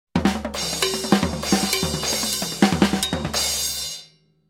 Анимационный герой падает со звуком спотыкания о предметы